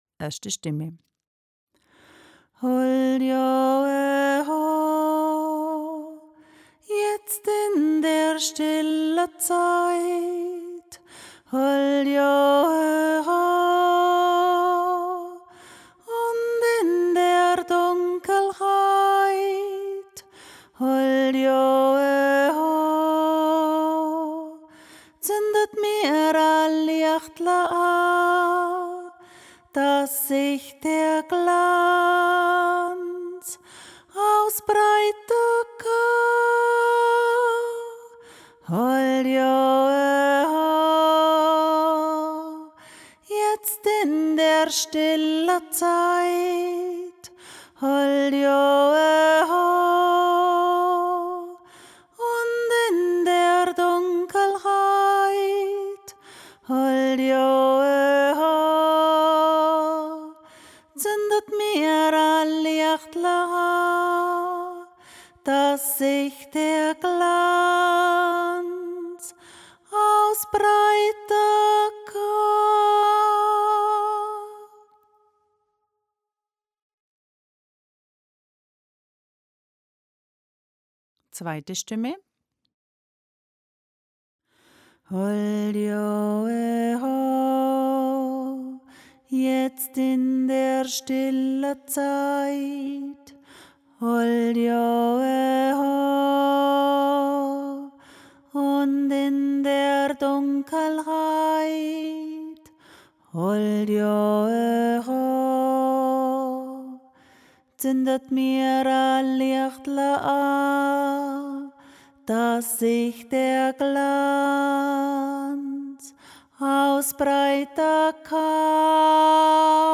Der Dezember JOIK
dezember-joik-einzelstimmen.mp3